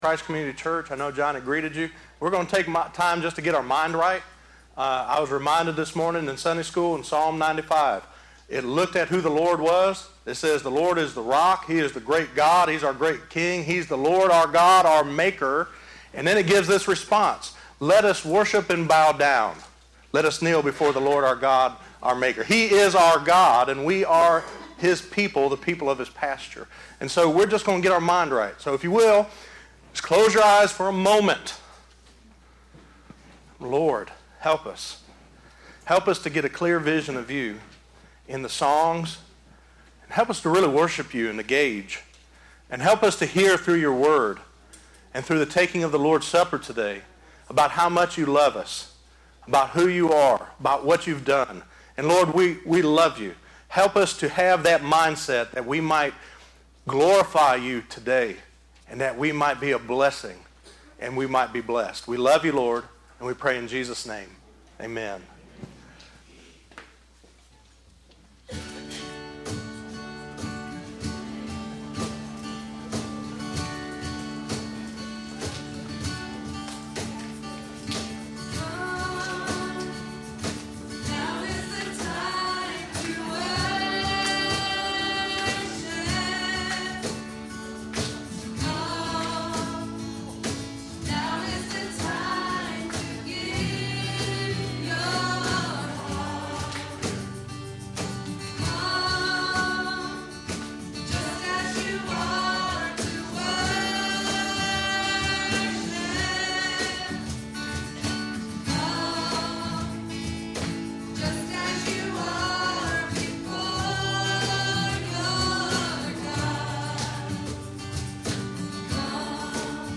The Gospel of Jesus Christ he Son of God 14 - Messages from Christ Community Church.